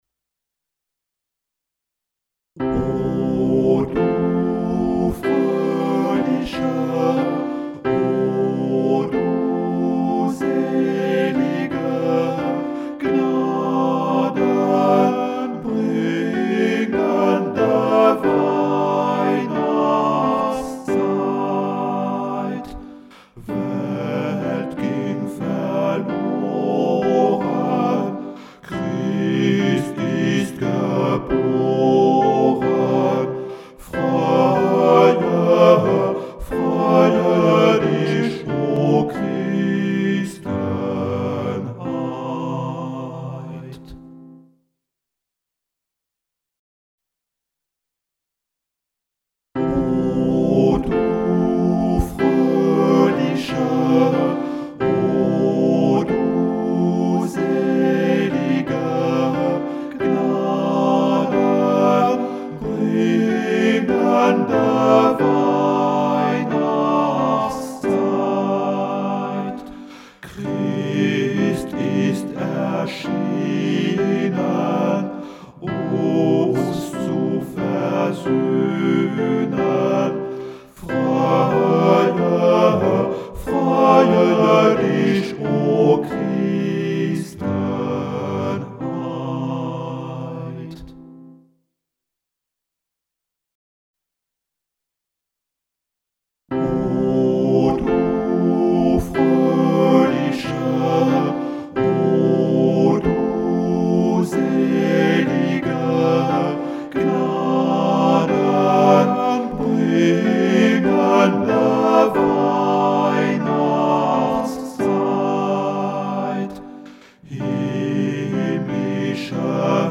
32_noel_tenor.mp3